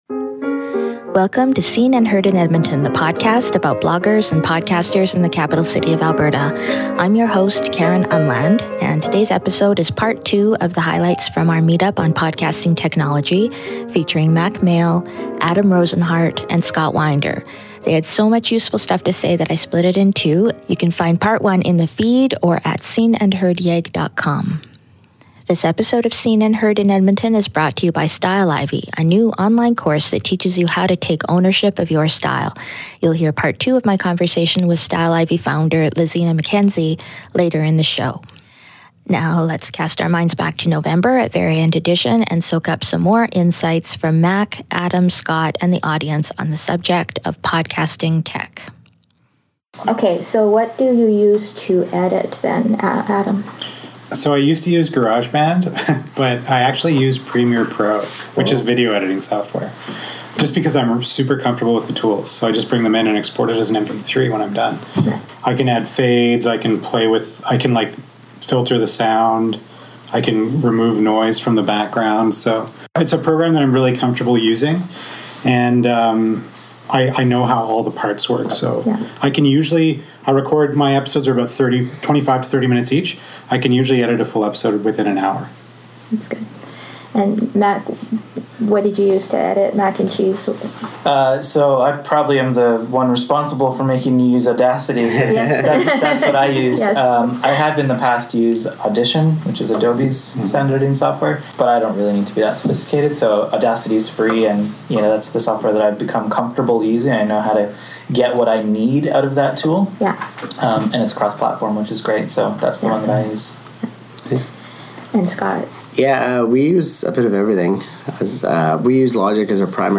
This week’s episode is Part 2 of the audio from our podcasting meetup on technology, which we held in November.